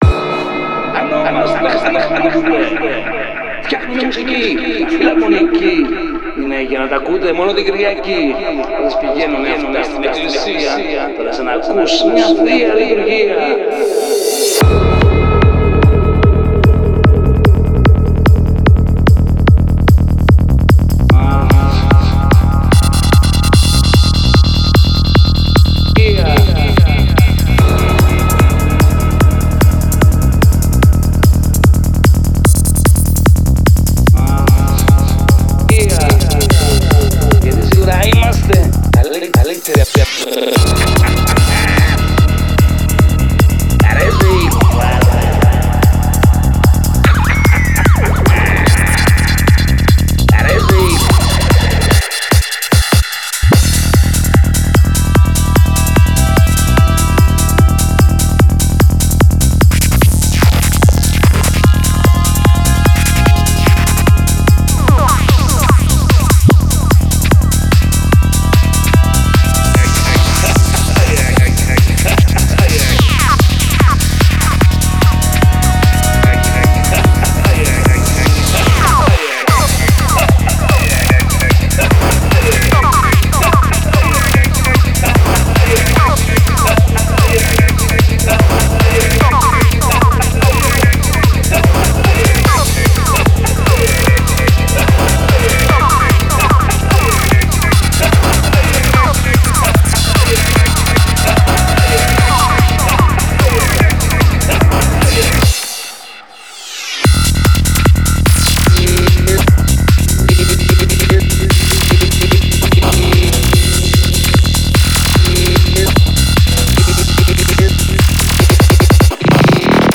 Style: Dark Psytrance